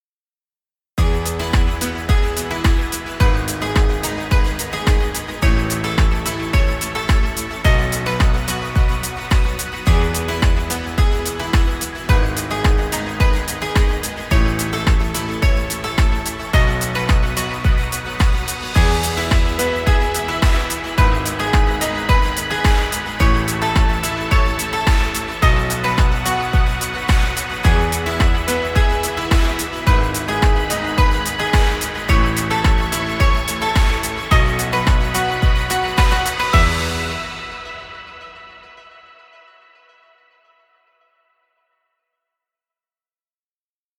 Happy corporate music.